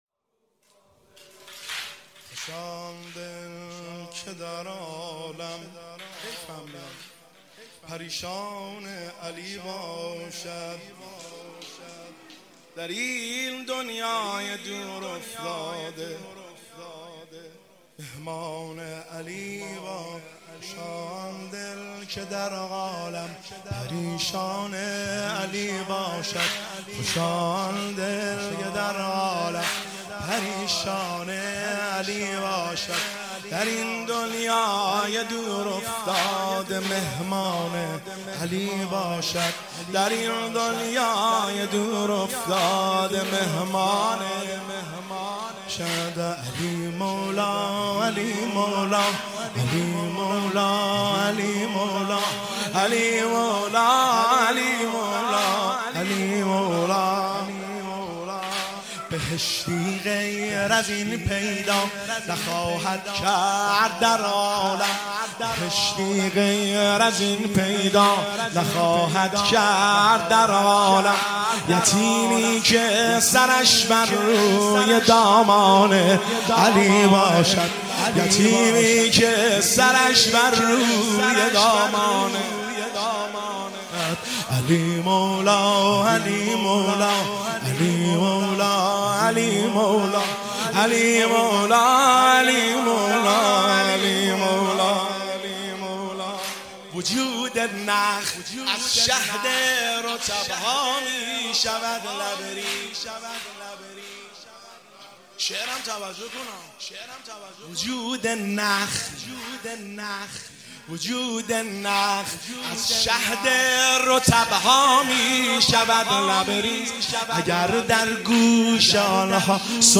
واحد ایام فاطمیه اول 1402 هیئت بیت الاحزان کاشان